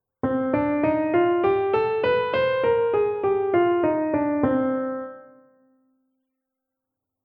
Verbleibt als dritte im Bunde noch die melodische Moll-Tonleiter.
Bei dieser wird auch die 6.%nbsp;Stufe um einen halben Ton erhöht (aufgelöst).
Da der Leitton nur aufwärts zum Grundton Sinn ergibt, wird er abwärts weggelassen und es wird die natürliche (reine) Moll-Tonleiter gespielt.
TonleiternMollMelodisch.mp3